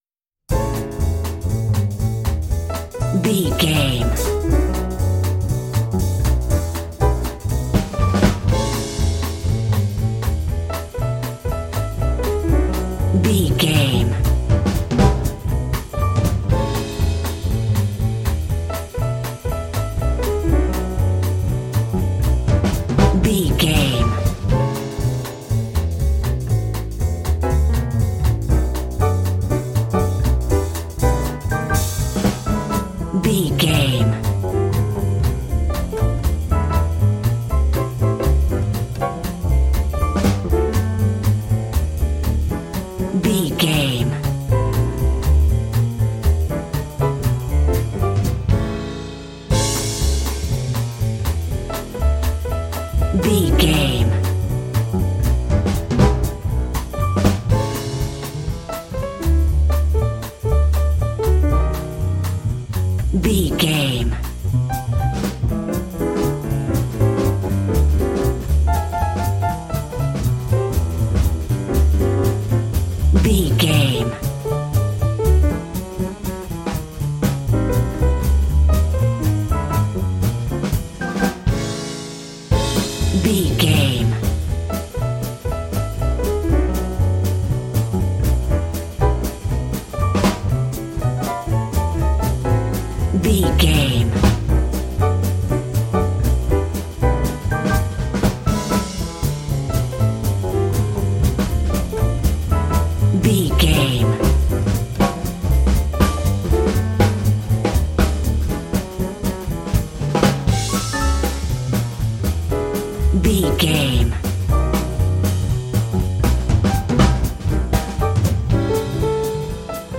Ionian/Major
Fast
energetic
groovy
piano
electric guitar
drums
double bass
bebop swing
jazz